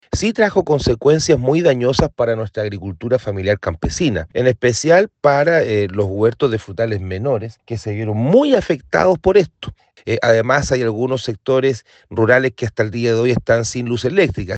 El alcalde Alexis Pineda explicó que la afectación es a la agricultura familiar campesina, en especial para “los huertos frutales menores”.
alcalde-granizos.mp3